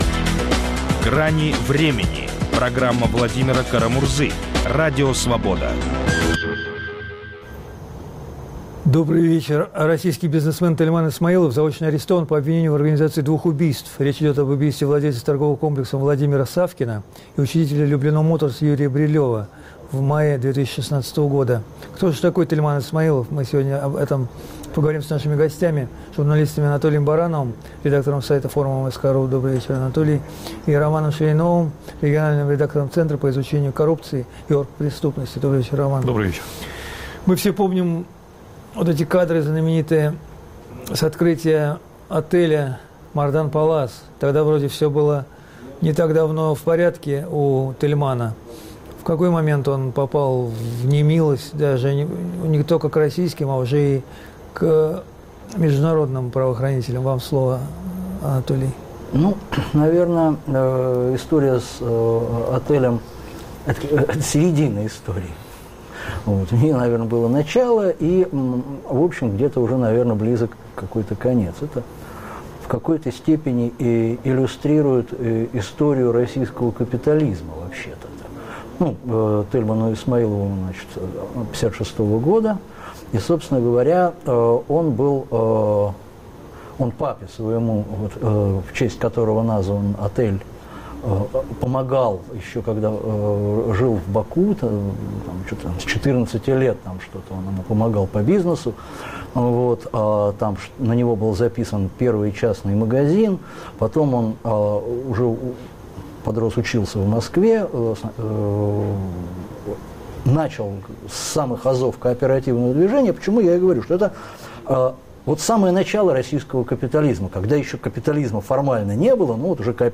Кто такой Тельман Исмаилов? Обсуждают журналисты